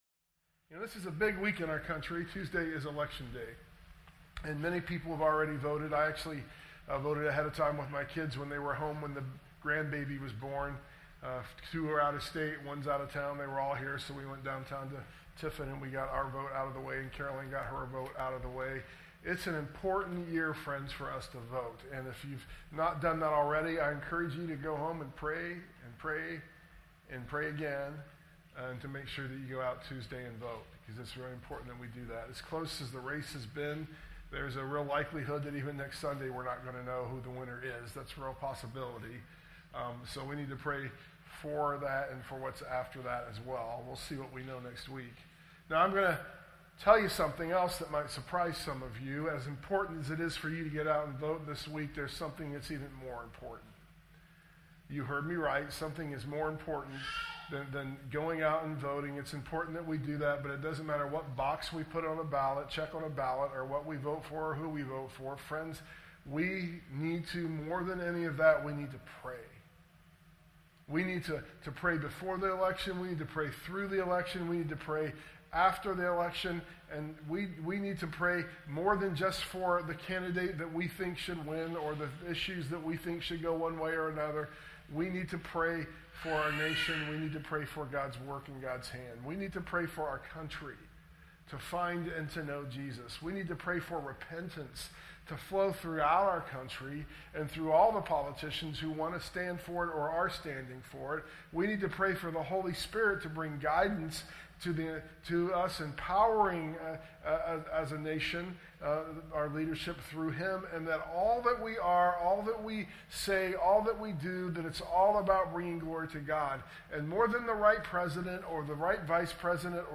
sermon_audio_mixdown_11_3_24.mp3